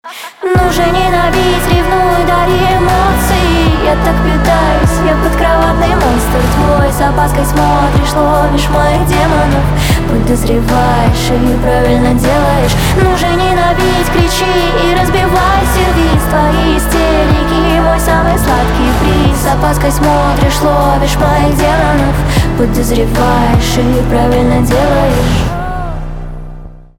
инди
басы , битовые , хлопки